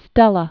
(stĕlə), Frank Philip Born 1936.